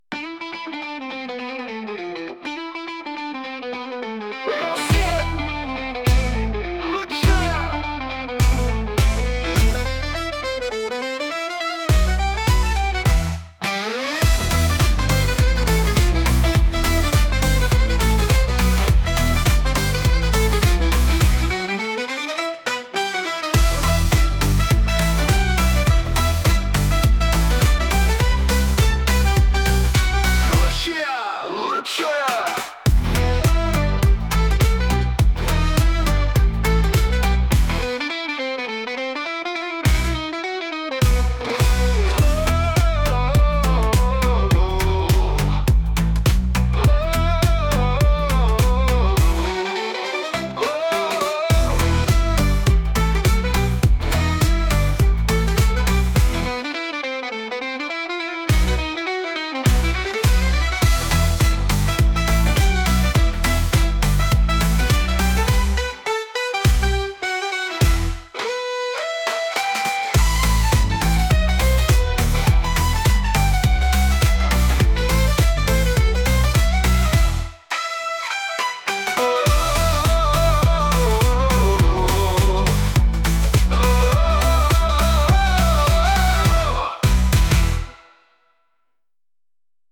Музыка с минимум слов